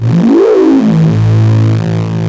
OSCAR 10 A1.wav